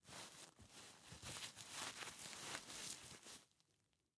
take_diaper.ogg